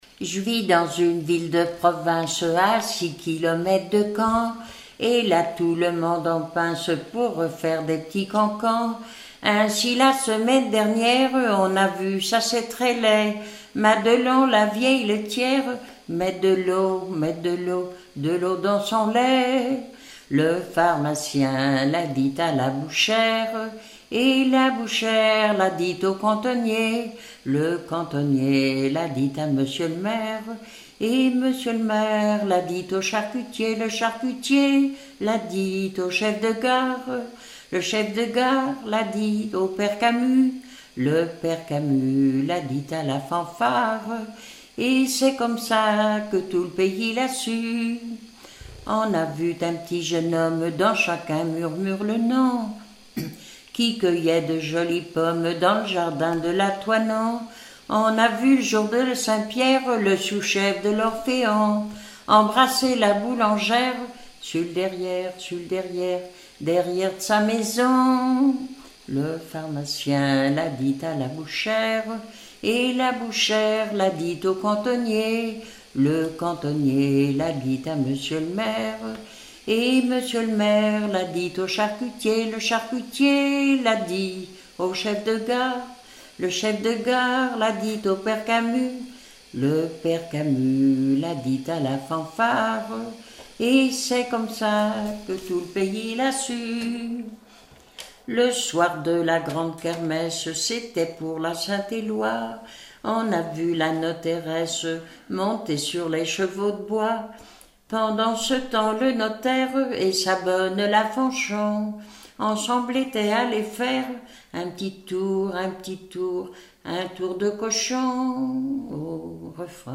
Saint-Vincent-Sterlange
Genre strophique
Pièce musicale inédite